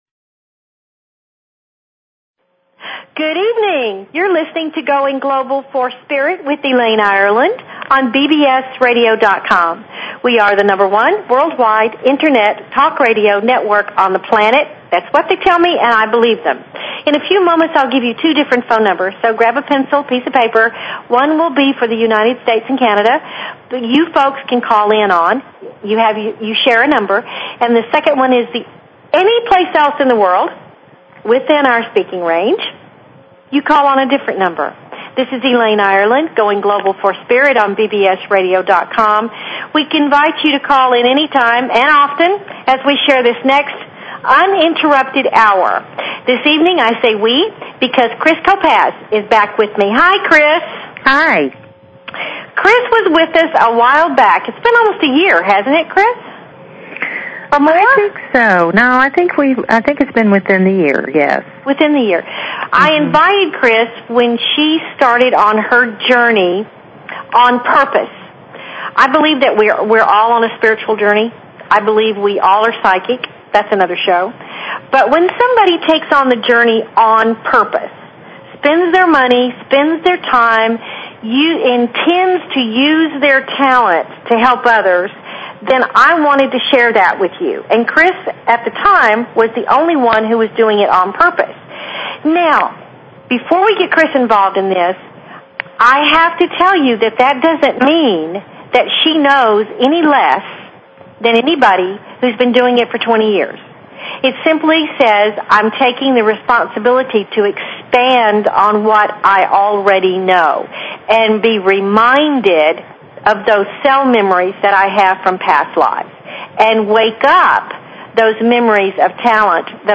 Talk Show Episode, Audio Podcast, Going_Global_for_Spirit and Courtesy of BBS Radio on , show guests , about , categorized as
A variety of guests will be here to teach and share their wonders with you. They invite you to call in with your questions and comments about everything metaphysical and spiritual!"